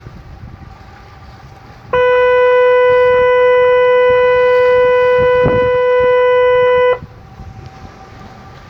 Róg mgłowy Gedanii
Gedania-sygnal-Horn.mp3